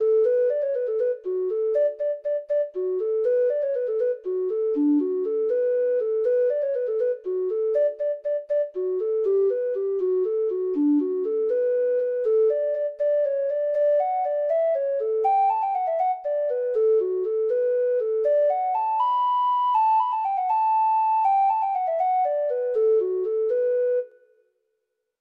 Traditional